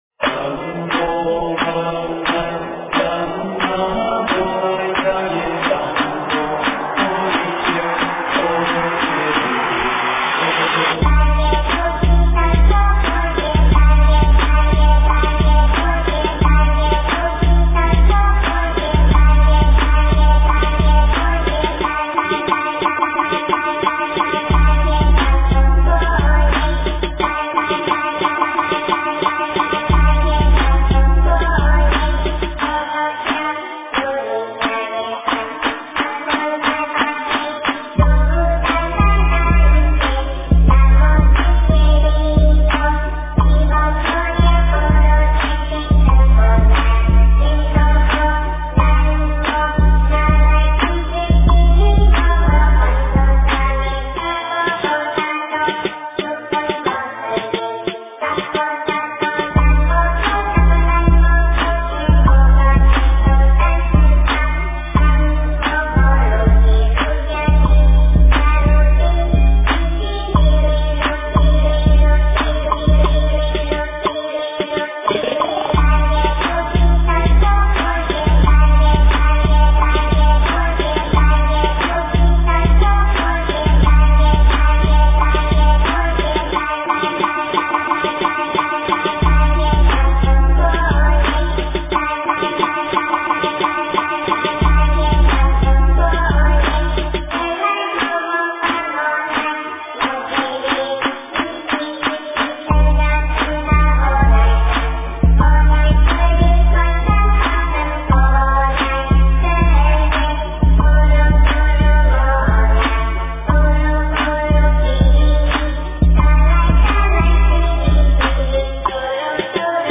诵经
佛音 诵经 佛教音乐 返回列表 上一篇： 心经 下一篇： 心经 相关文章 福慧偈--清净莲音 福慧偈--清净莲音...